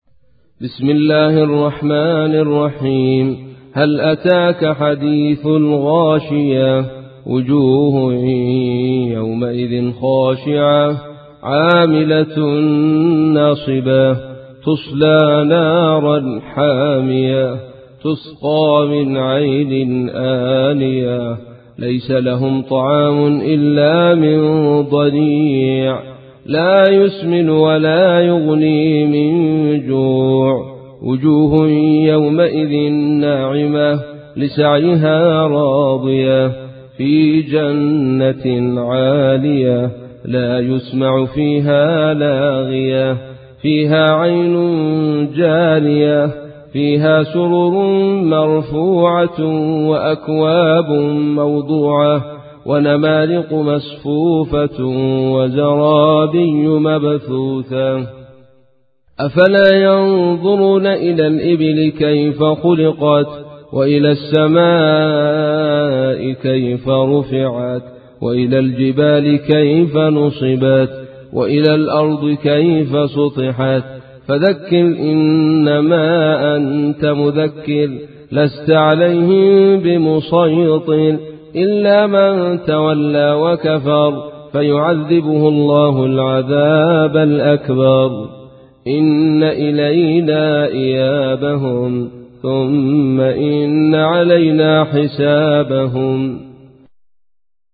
تحميل : 88. سورة الغاشية / القارئ عبد الرشيد صوفي / القرآن الكريم / موقع يا حسين